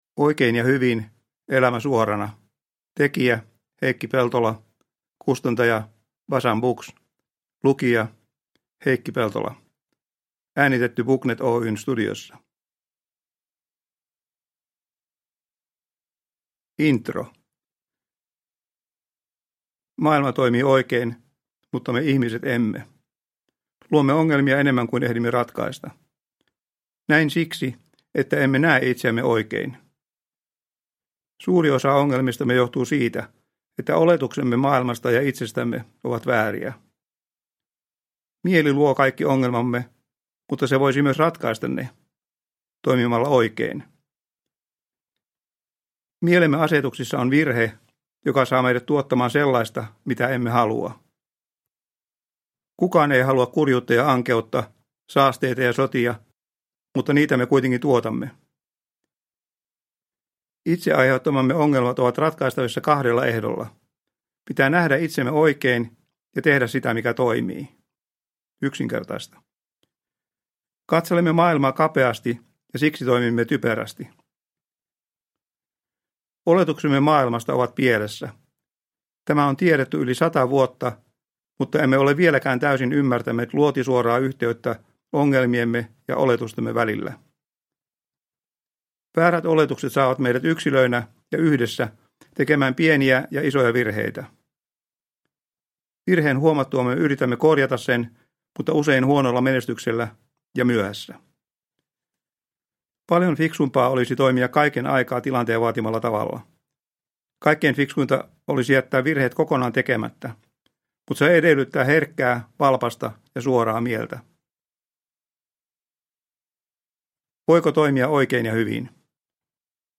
Oikein ja hyvin – Ljudbok – Laddas ner
Produkttyp: Digitala böcker